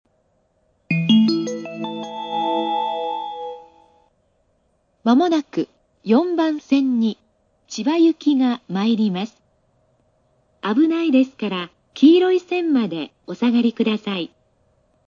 スピーカー：National
音質：A
接近放送　(74KB/15秒)